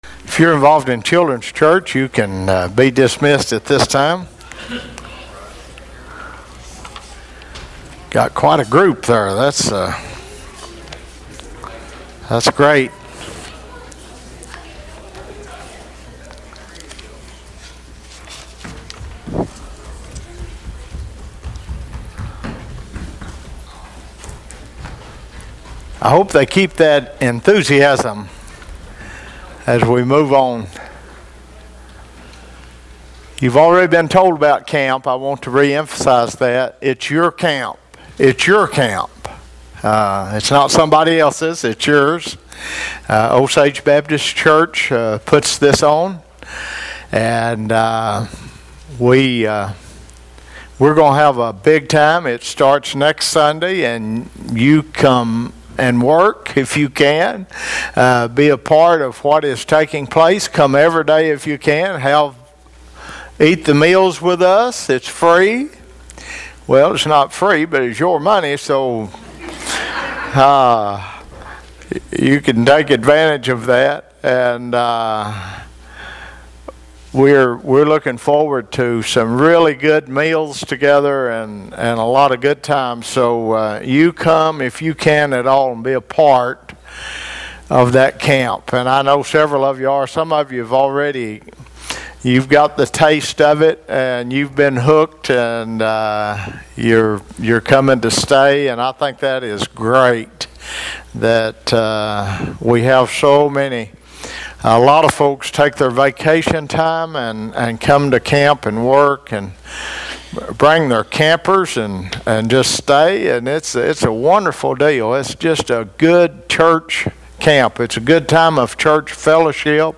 Required fields are marked * Comment * Name * Email * Website ← Newer Sermon Older Sermon →